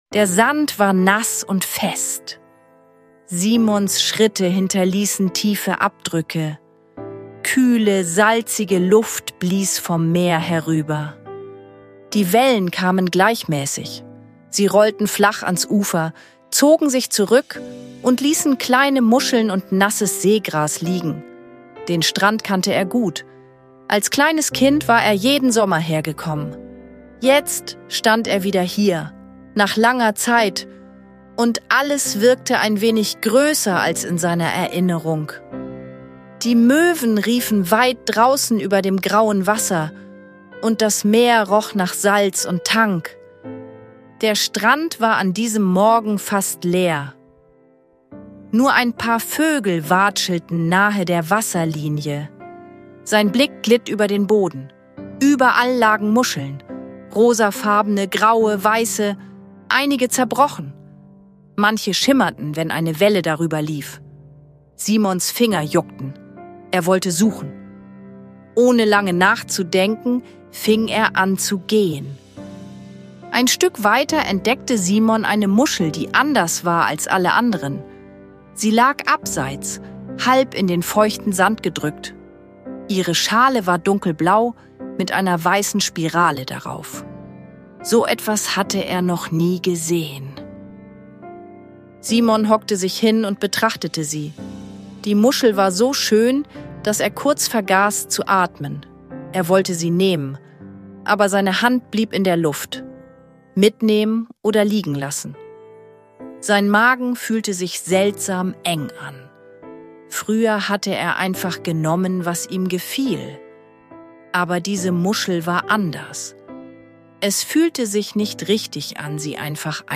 Beschreibung vor 1 Monat Simon läuft an einem stillen Strand entlang und entdeckt eine Muschel, die ihn innehalten lässt. Zwischen Meeresrauschen, Wind und warmen Erinnerungen wächst in ihm eine leise Frage: Was fühlt sich wirklich richtig an? Eine sanfte Gute-Nacht-Geschichte voller Ruhe, Staunen und Geborgenheit – ideal für euer Abendritual.